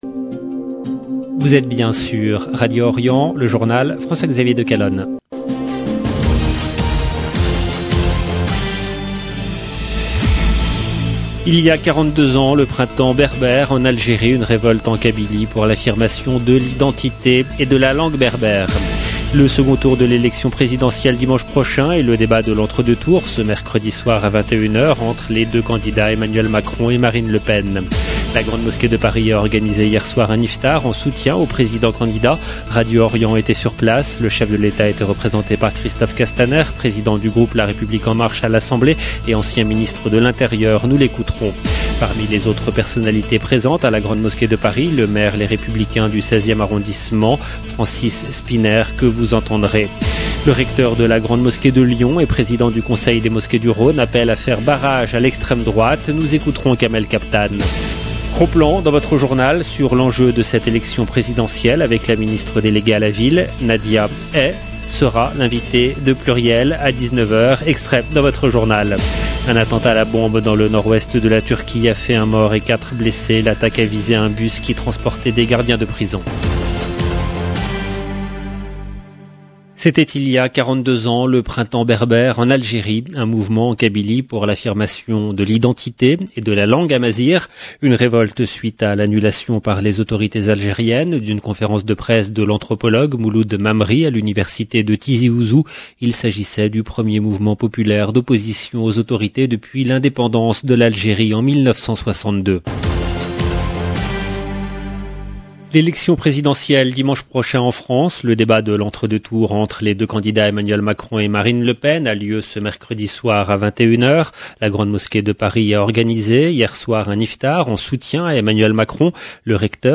LB JOURNAL EN LANGUE FRANÇAISE
Radio Orient était sur place.